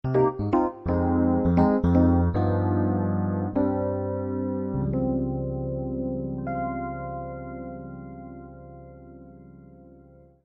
Example 5 Finally we transformed i, iv, V7, i into i7, VIb-6, iv7, V7-aug reading: c, Ab-6, f7, G7-aug.